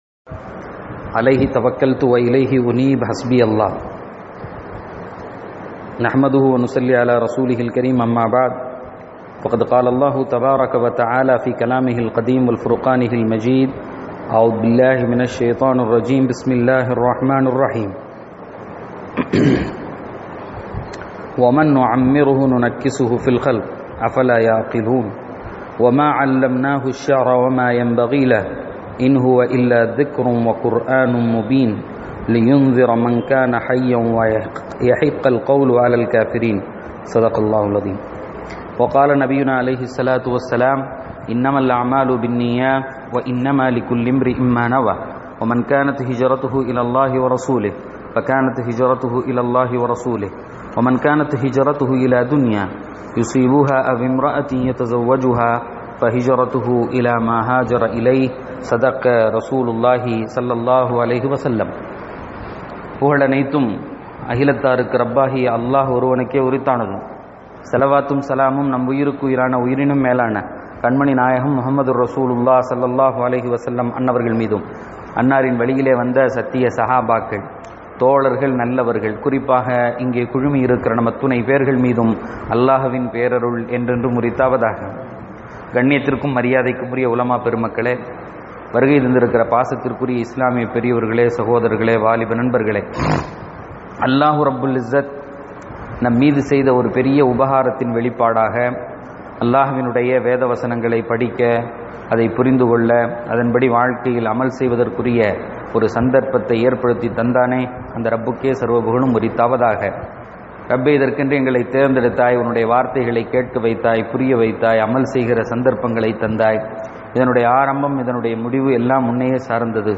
யாஸீன் விளக்கவுரை: ஆயத்: 68-69 (13-Aug-2017) 36:68.